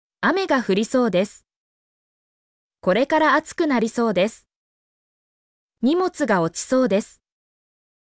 mp3-output-ttsfreedotcom-2_BOpScewy.mp3